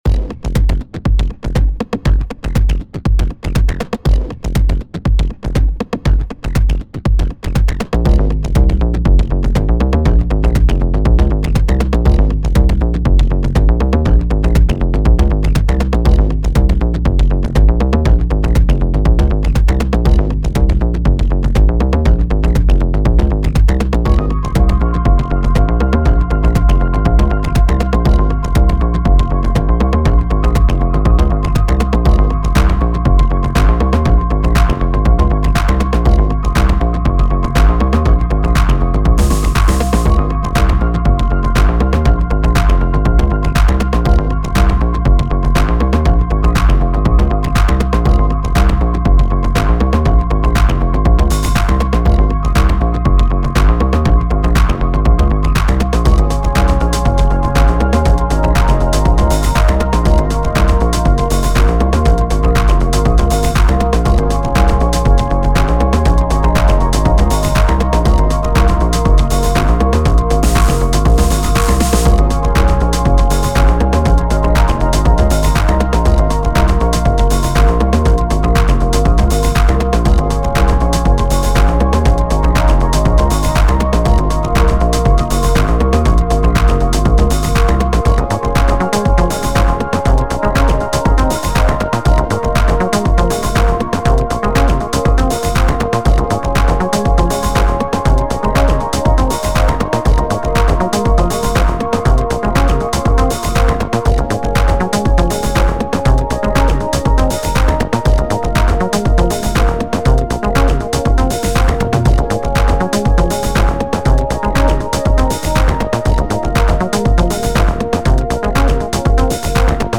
The sh101 style sequencing really is my favorite way of coming up with basslines